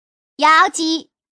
Index of /hunan_master/update/12815/res/sfx/changsha_woman/